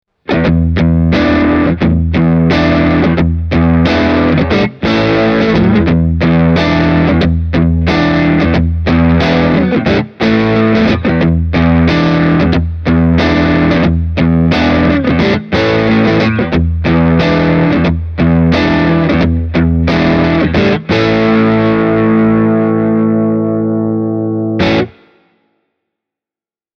Putting good descriptions to sounds is always rather hard – so take a listen to the soundbites I recorded for you, using my Fender ’62 Telecaster Custom -reissue, as well as my Hamer USA Studio Custom. All delays and reverbs have been added at during mixdown.
Telecaster – ”…Foxy…”
telecaster-e28093-foxy-crunch.mp3